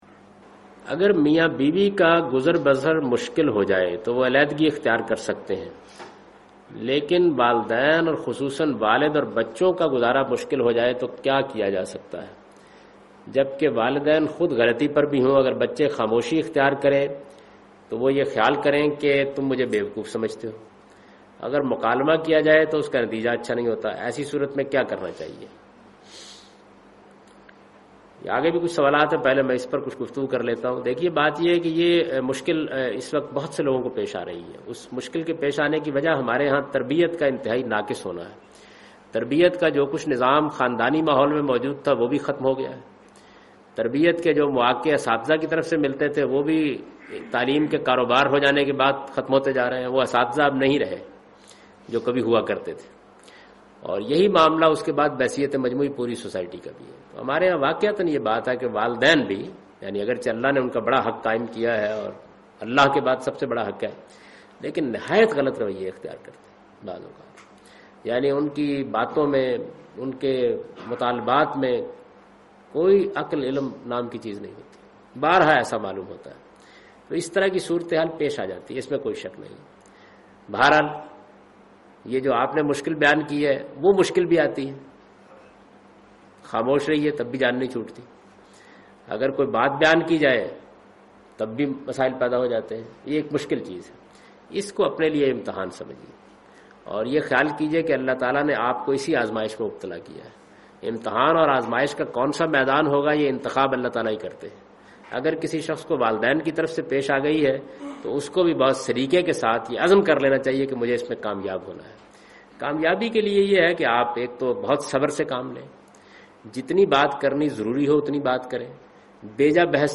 Javed Ahmad Ghamidi responds to the question ' What should be done if parents-children conflicts become insoluble'?